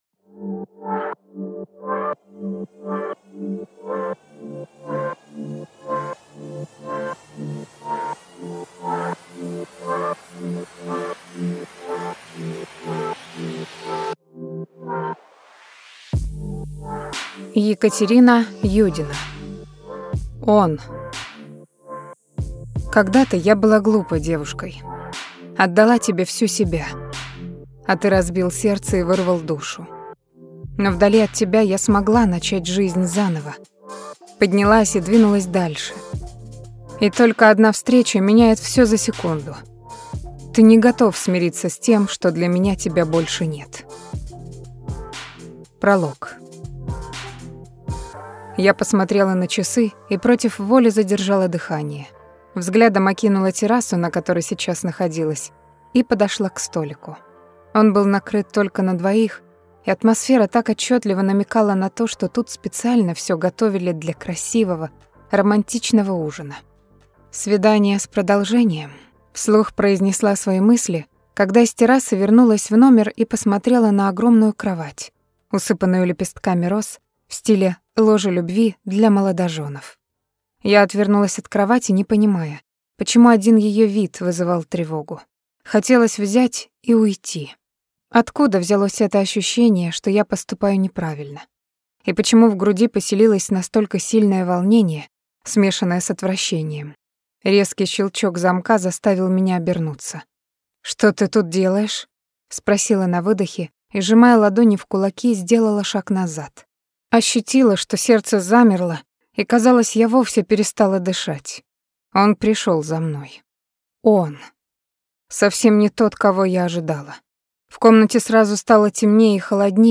Aудиокнига Он…